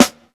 SNARE_WHOA_WHOA.wav